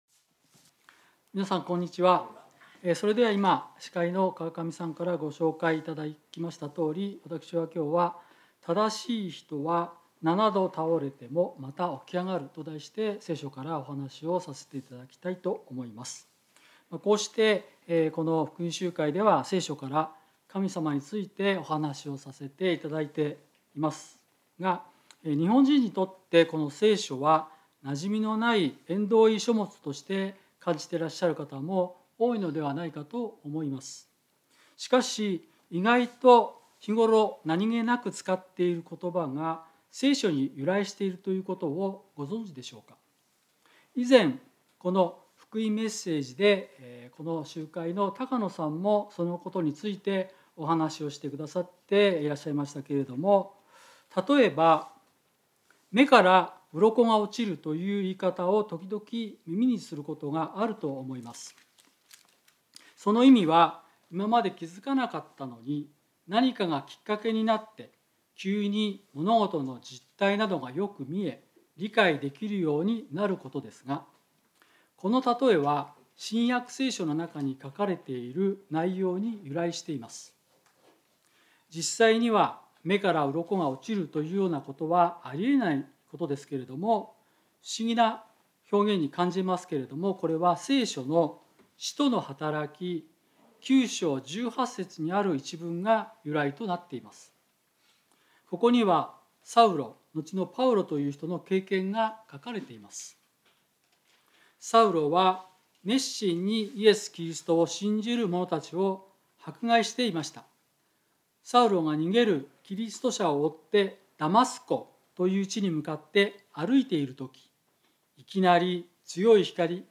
聖書メッセージ No.243